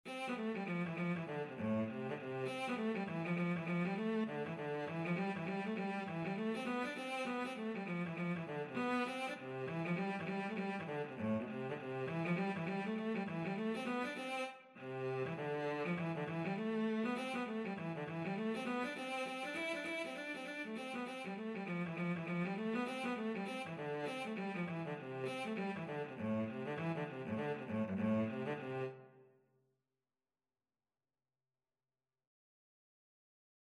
Cello version
2/4 (View more 2/4 Music)
G3-E5
Traditional (View more Traditional Cello Music)